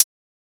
Closed Hats
edm-hihat-36.wav